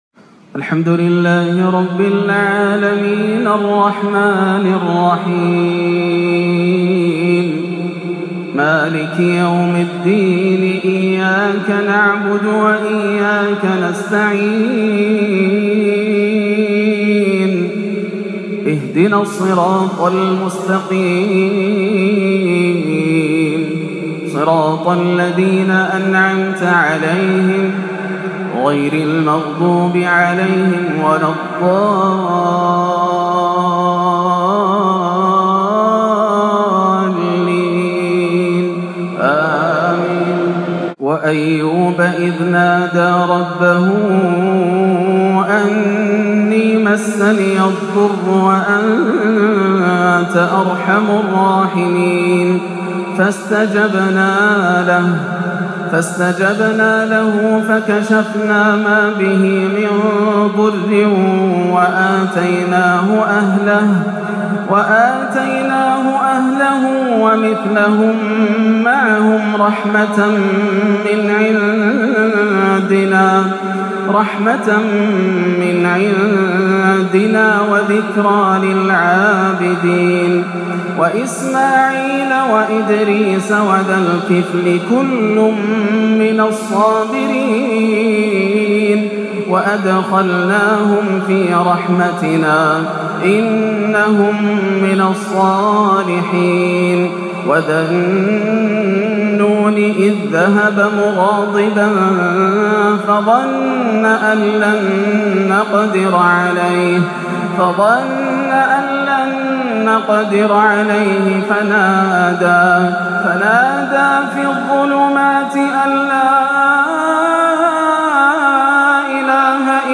تلاوة مؤثرة أبكت الشيخ ياسر الدوسري من سورتي الأنبياء و الحديد صلاة الجمعة ١٤٣٨/٤/١ > عام 1438 > الفروض - تلاوات ياسر الدوسري